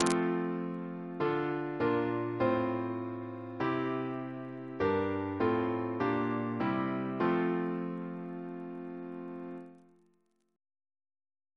Single chant in F Composer: Ray Francis Brown (1897-1965) Reference psalters: ACP: 249